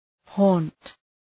Shkrimi fonetik {hɔ:nt}